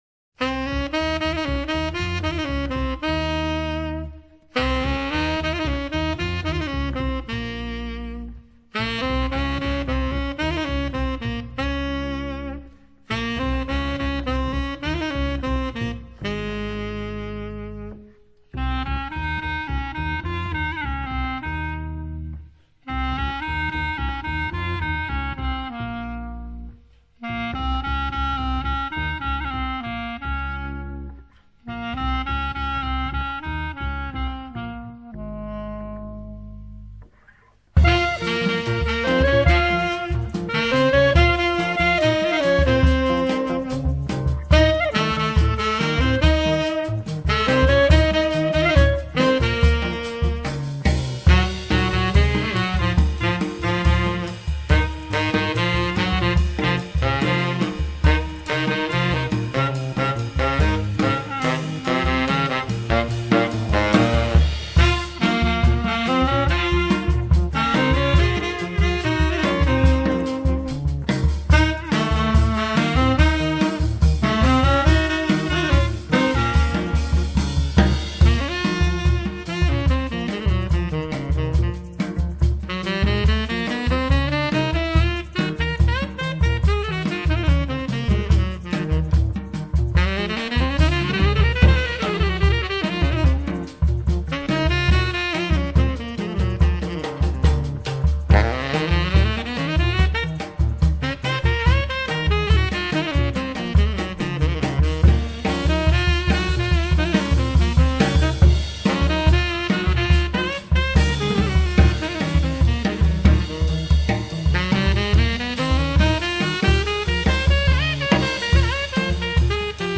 Recorded at Colori Studio on August 16,17,18 1999